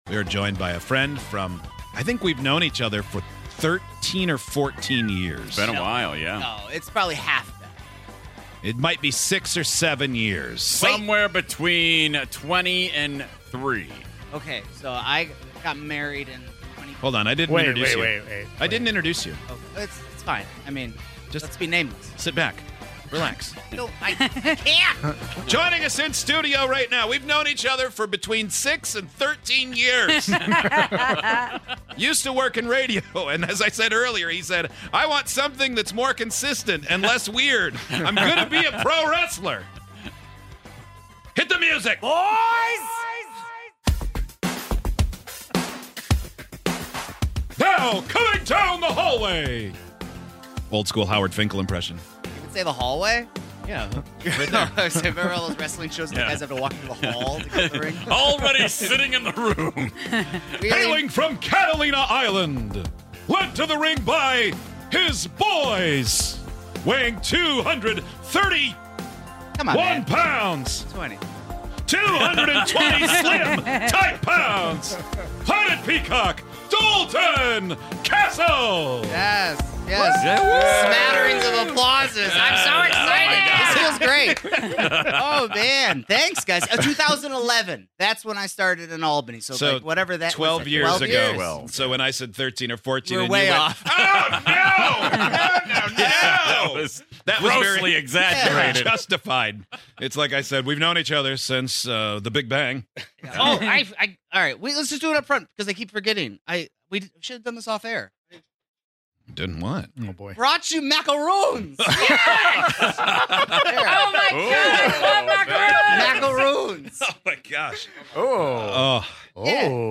On today's show, our favorite wrestler Dalton Castle joined us in studio today.